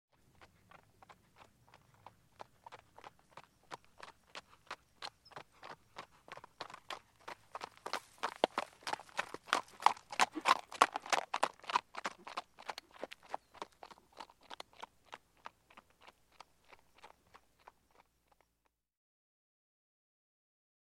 جلوه های صوتی
دانلود صدای اسب 95 از ساعد نیوز با لینک مستقیم و کیفیت بالا